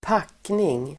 Uttal: [²p'ak:ning]
packning.mp3